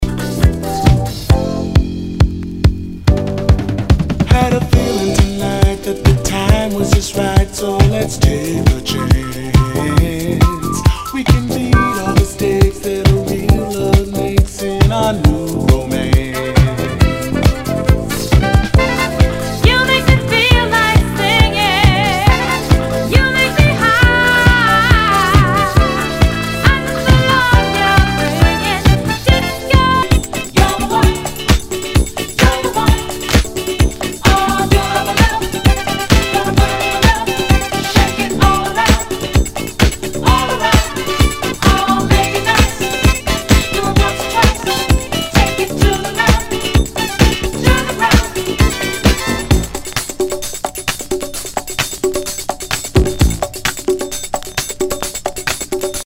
SOUL/FUNK/DISCO